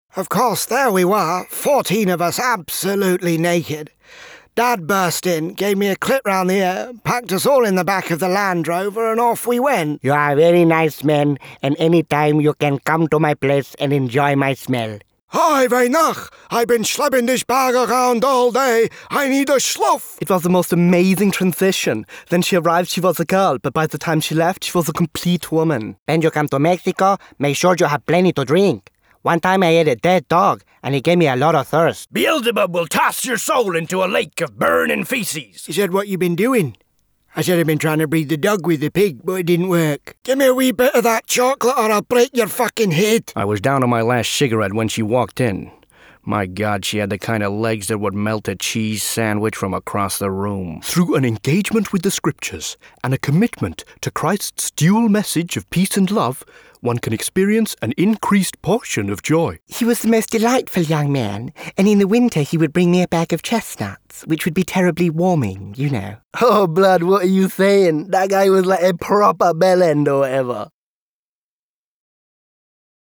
I specialise in character voices and celebrity impersonations.
My natural voice is lively and warm, and my characters are… whatever you need!
Voicereel-Characters.m4a